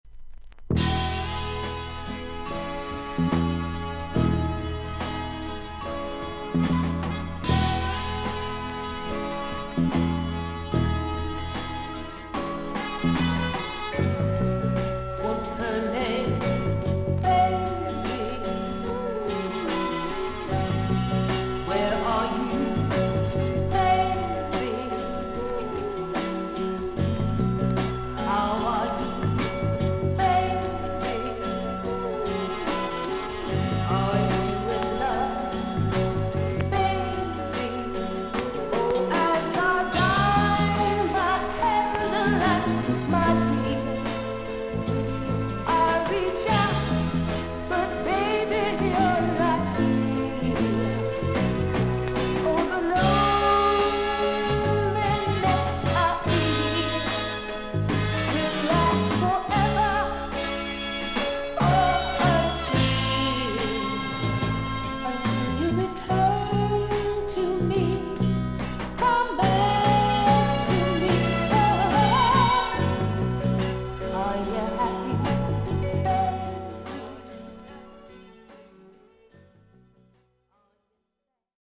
曲がはじまって30秒から8回ほどパチノイズあり。
少々サーフィス・ノイズあり。クリアな音です。
女性ソウル・シンガー。